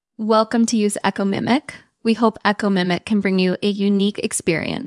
echomimic_en_girl.wav